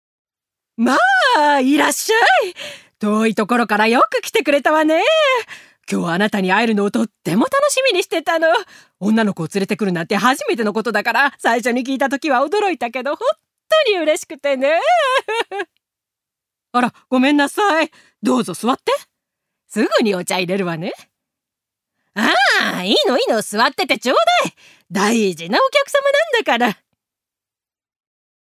女性タレント
セリフ２